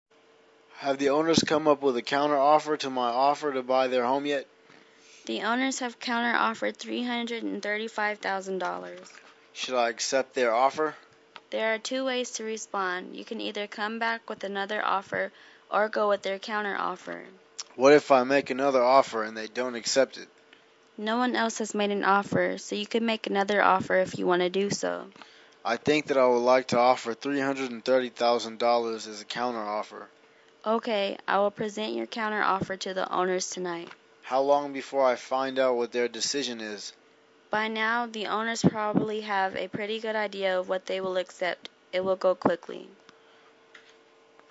英语情景对话-Discussing Counter-Offer(1) 听力文件下载—在线英语听力室